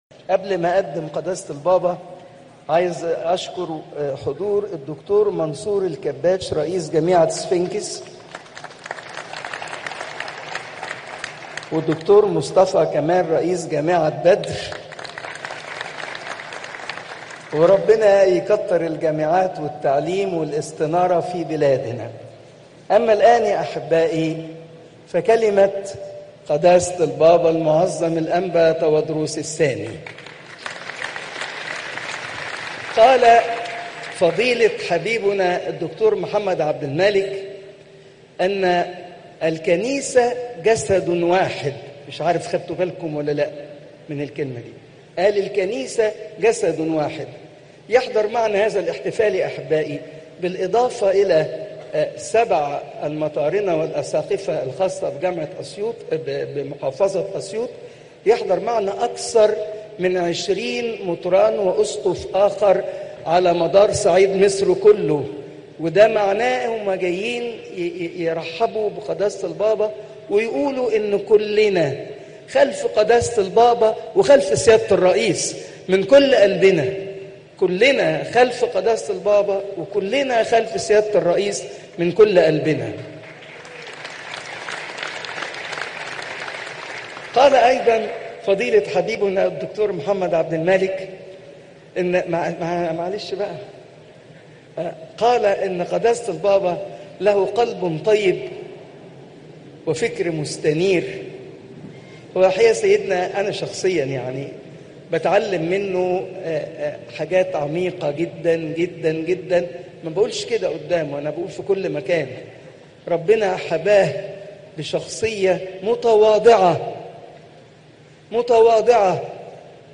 Pope Tawdroes II Weekly Lecture